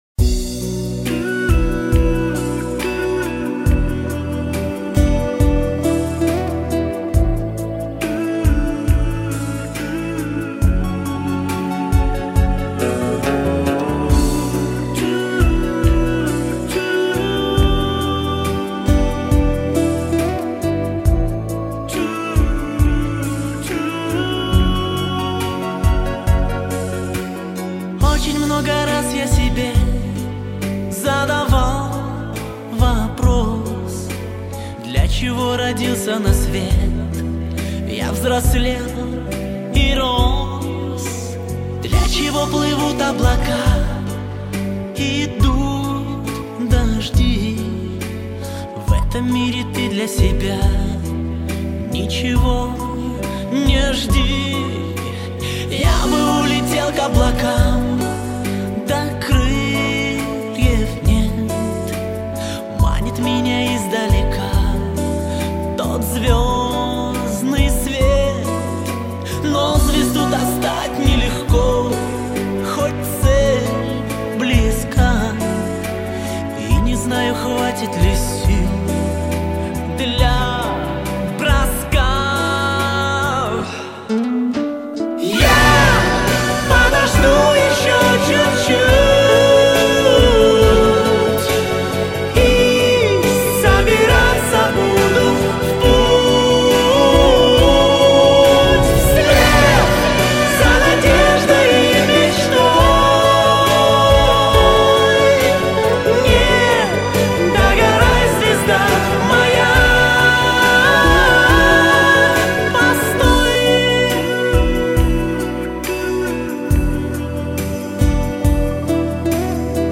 整张专辑旋律亲切，非常耐听。